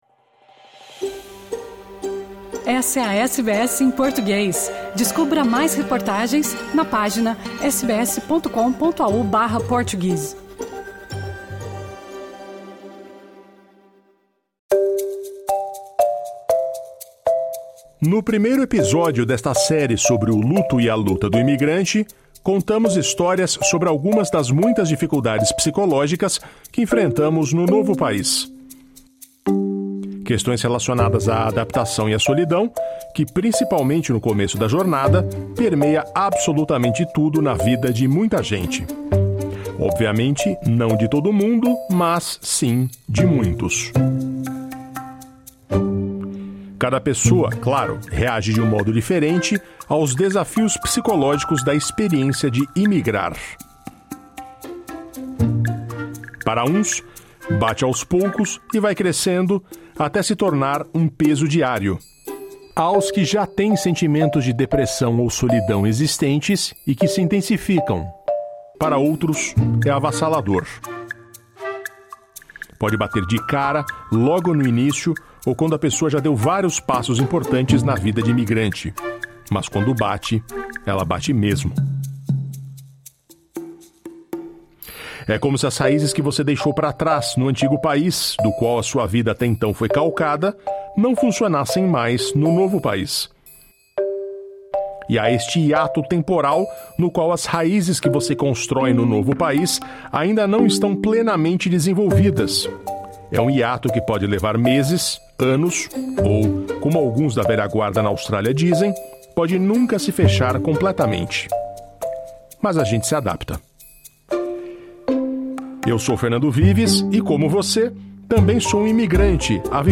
Nesta entrevista para a série especial 'A Luta e o Luto do Imigrante'